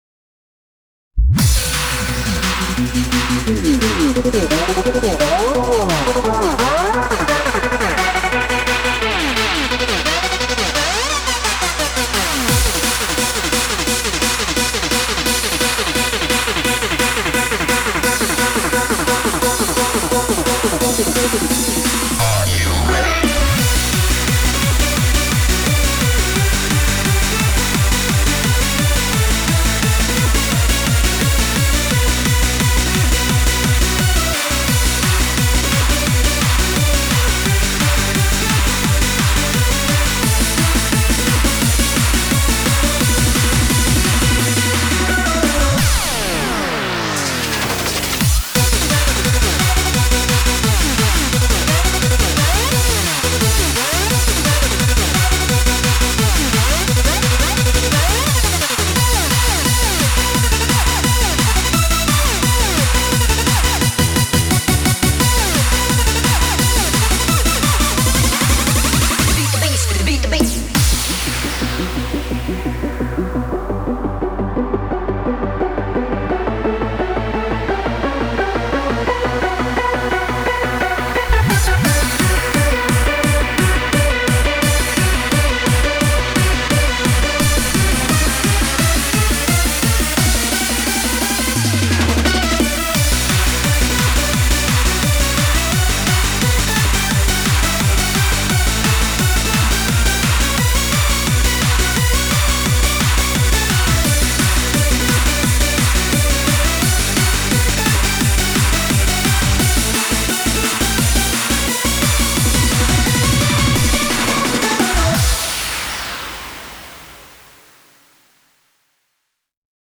BPM170-340
Audio QualityPerfect (High Quality)